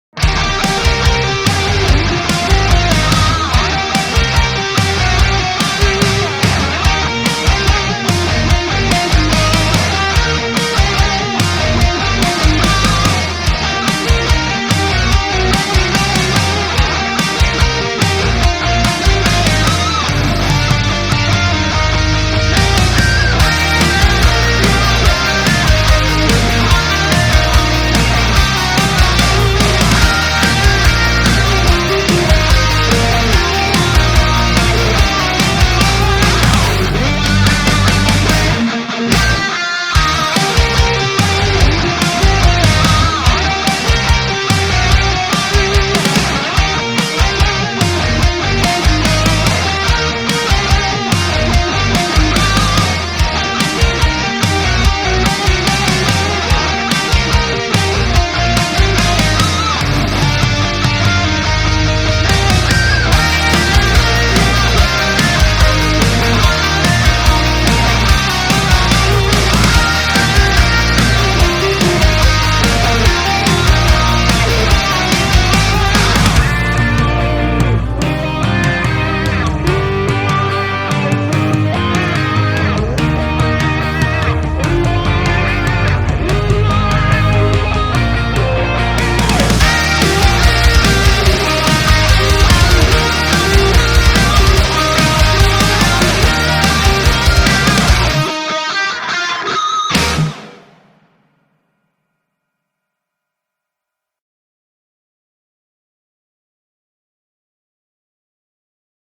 Metal_Rock Guitar Cover Remix